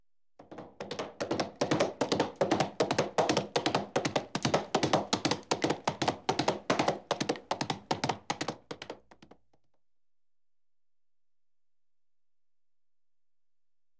Galloping Horse
animal galloping hoofs horse sound effect free sound royalty free Animals